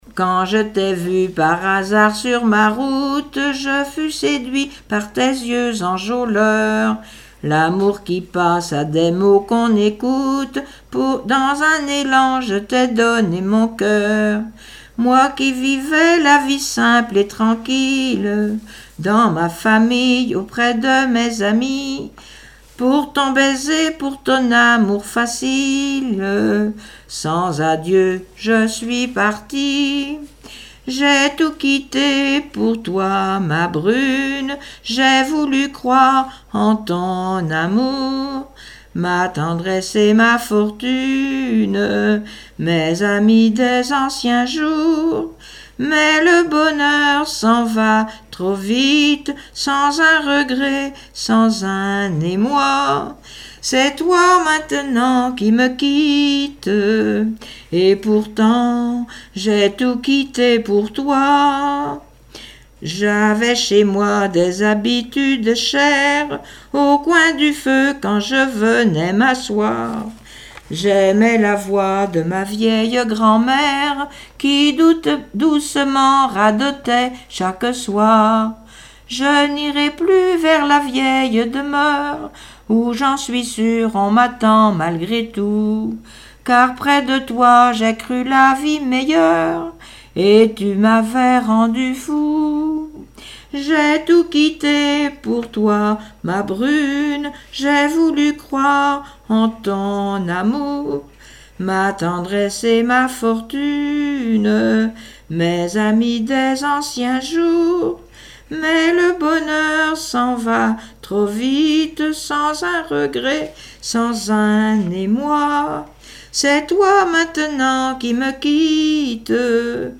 Mémoires et Patrimoines vivants - RaddO est une base de données d'archives iconographiques et sonores.
Genre strophique
Témoignages et chansons
Pièce musicale inédite